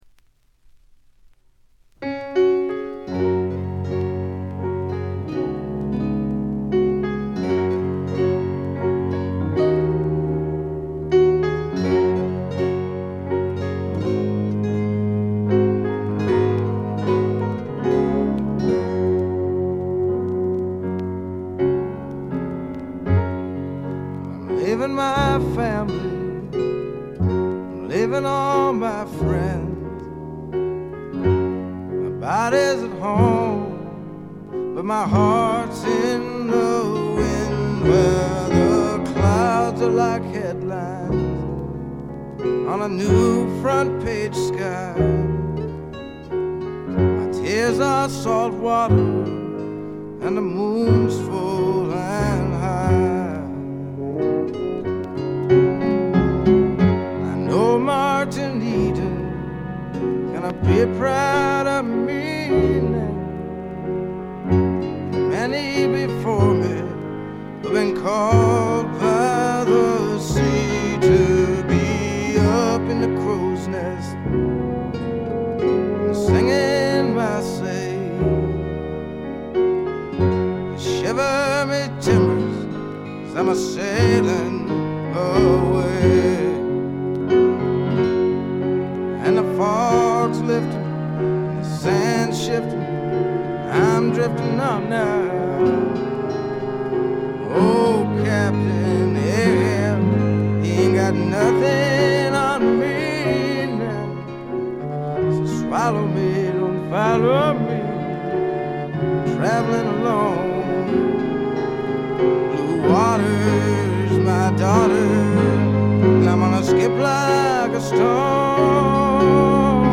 軽微なチリプチ程度。
メランコリックでぞっとするほど美しい、初期の名作中の名作です。
試聴曲は現品からの取り込み音源です。